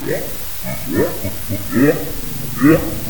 The examples below are short samples of recordings of vocalizations that were captured in the humpback whale feeding waters of Southeast Alaska.
These vocalizations were recorded on an Intersea Foundation expedition.